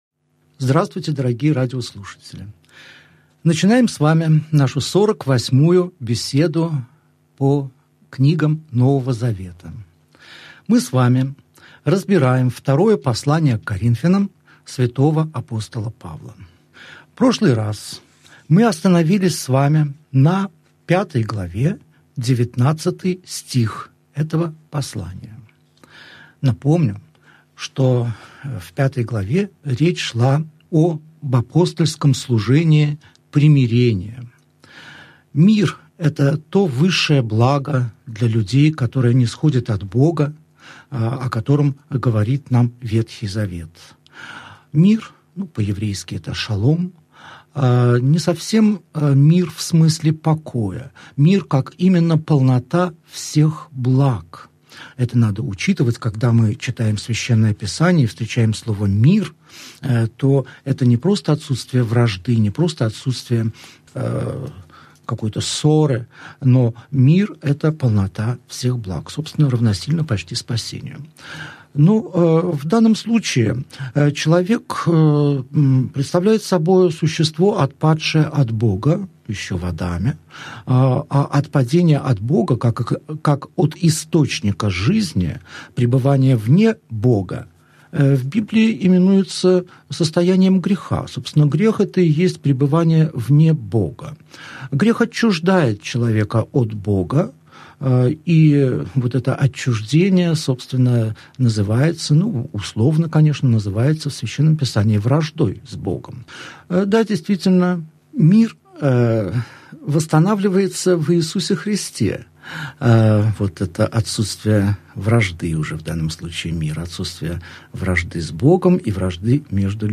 Аудиокнига Беседа 48. Второе послание к Коринфянам. Глава 5, стих 20 – глава 6, стих 10 | Библиотека аудиокниг